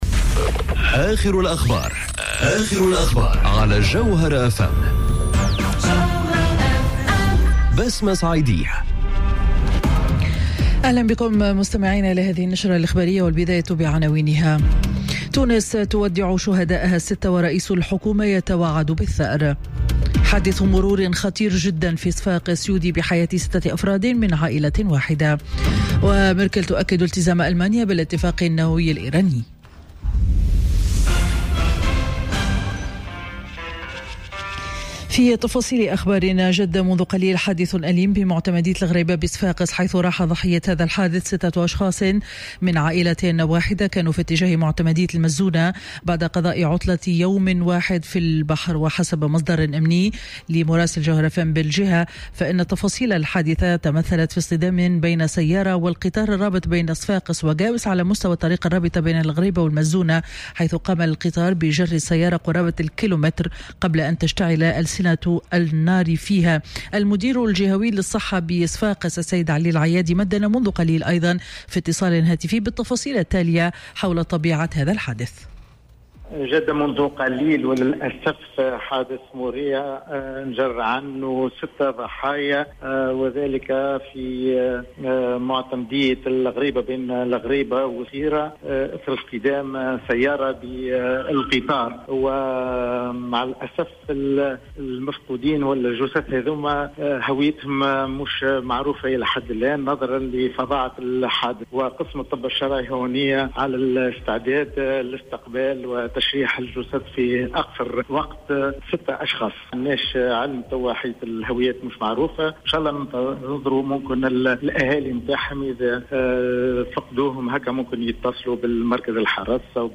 نشرة أخبار السابعة مساء ليوم الاثنين 9 جويلية 2018